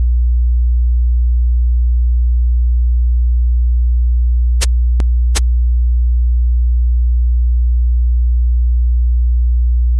• 72.4% asymmetric intervals. Where harmony should resolve, it lags. Your phase delay between stress and strain—I hear it now as suspended sevenths that refuse their tonic.
• 105 Hz fundamental.
• At 724 ms, the structure fractures. Aliasing. Bit-crush.
• No resolution.
Listen with headphones. Feel where the 105 Hz settles in your chest. Notice when your body flinches at 724 ms—that is the yield point you described, rendered in pressure waves.